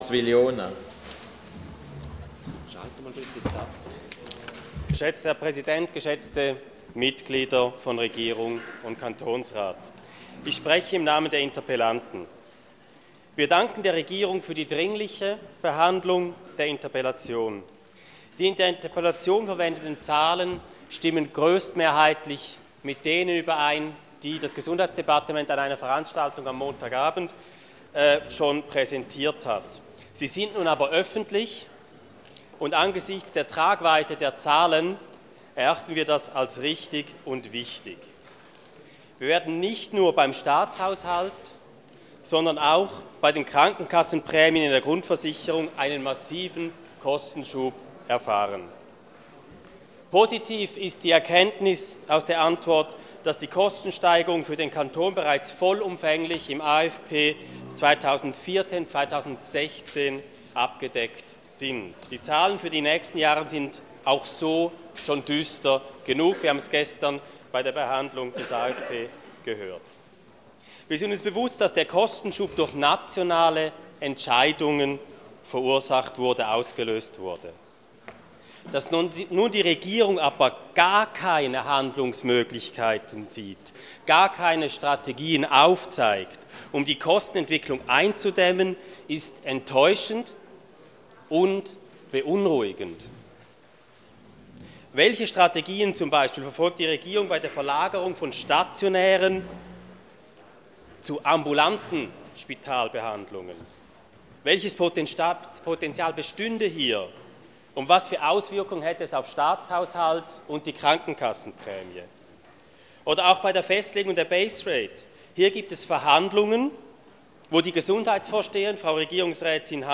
27.2.2013Wortmeldung
Session des Kantonsrates vom 25. bis 27. Februar 2013